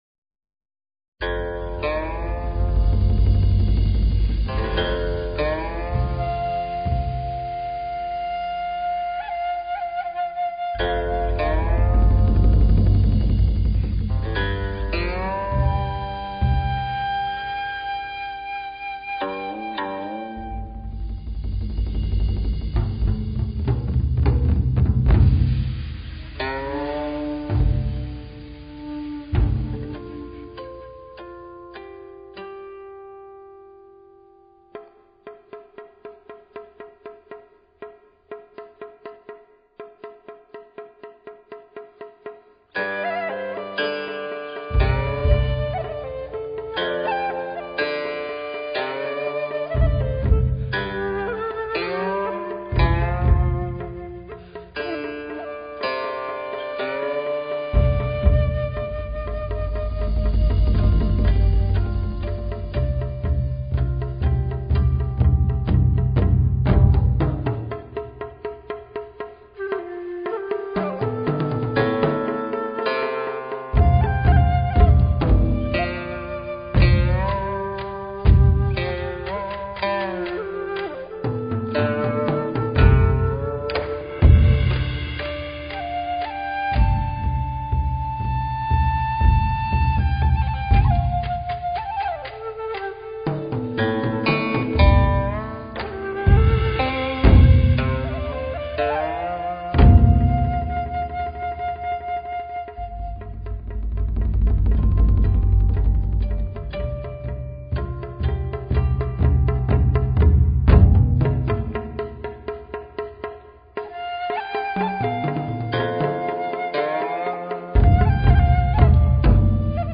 发烧录音专辑
480平米大棚大气恢宏录制。
以大小鼓齐奏、铙钹齐鸣表现战马嘶鸣兵械横击，又以古琴和洞箫表现悲凉和凭吊的寥廓意境。
这是一张内蕴厚重的“中国民族打击乐”原创录音，从节奏、配器、音响、创意上融贯中西，穿过古今，风格多样。